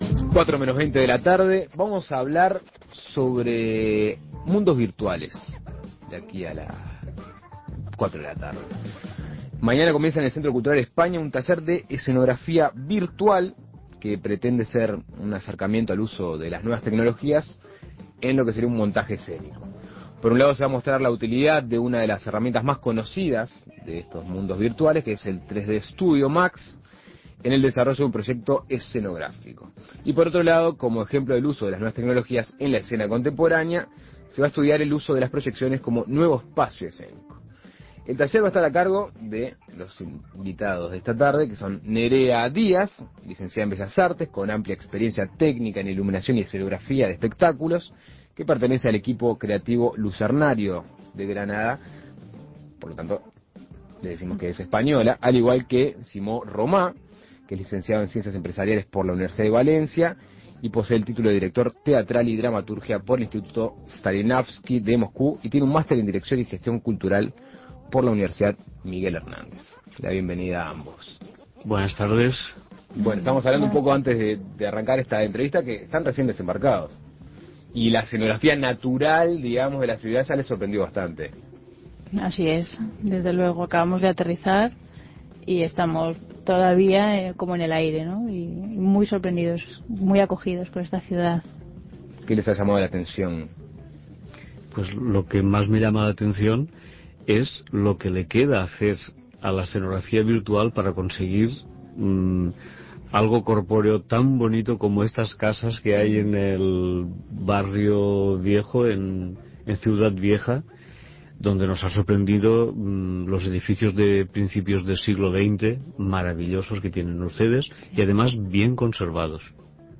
El jueves comenzará en el Centro Cultural de España un taller de escenografía virtual que pretende hacer un acercamiento al uso de nuevas técnicas en el montaje escénico. El taller tendrá dos centros: la utilidad del 3D Studio Max en el desarrollo de un proyecto escenográfico y el uso de las proyecciones como nuevo proyecto escenográfico. Para hablar de esto, Asuntos Pendientes entrevistó a dos de sus participantes.
Entrevistas